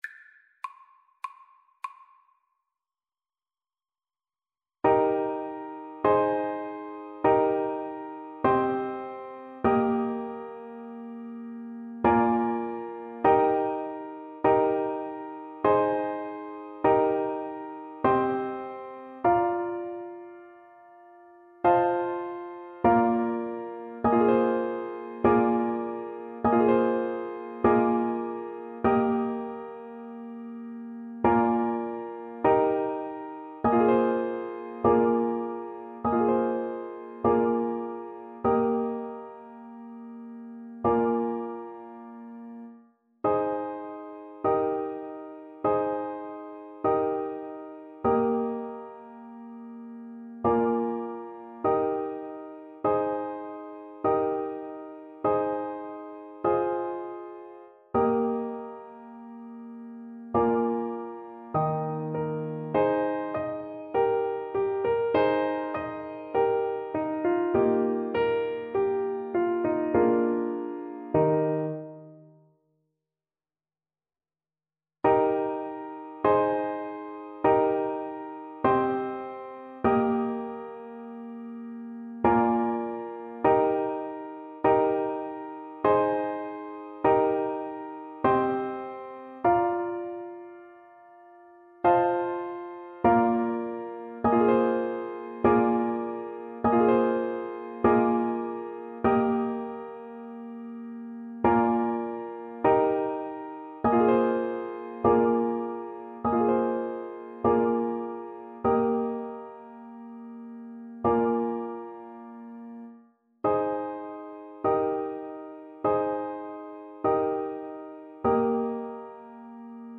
Moderato
Classical (View more Classical Clarinet Music)